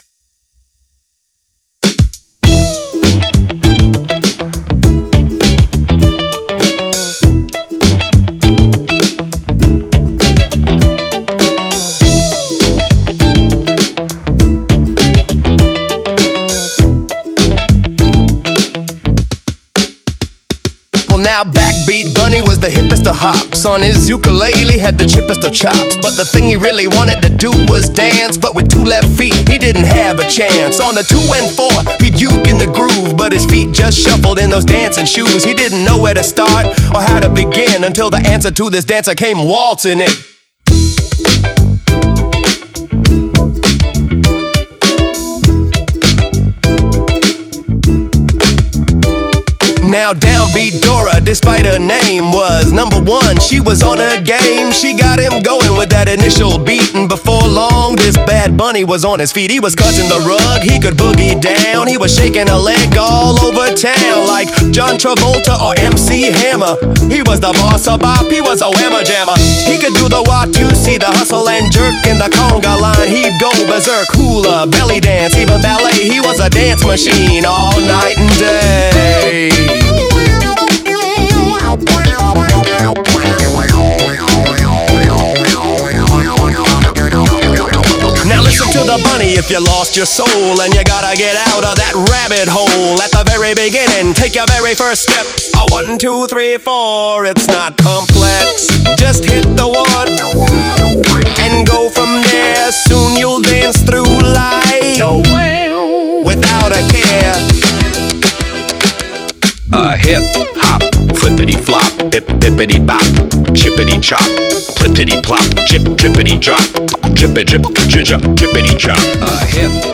So, I came up with this silly little “hip-hop” (get it?) masterpiece 🐇
BackBeat-Bunny-with-uke.mp3